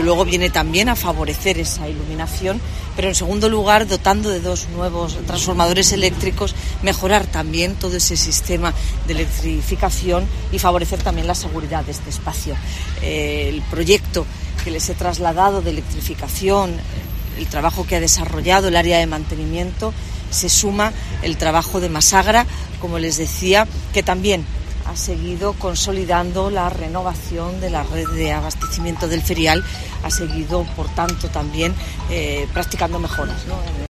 Marifrán Carazo, alcaldesa de Granada